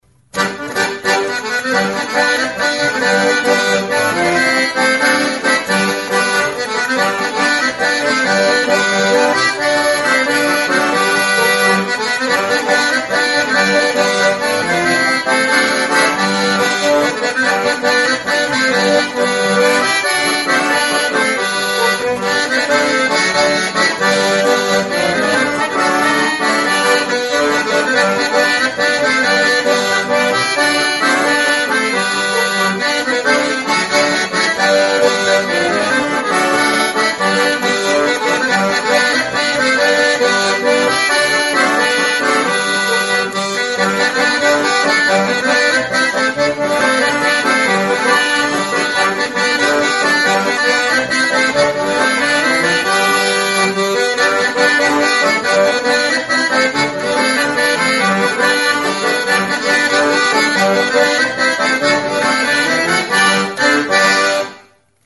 Aerophones -> Reeds -> Single Free
Recorded with this music instrument.
AKORDEOIA; AKORDEOI KROMATIKOA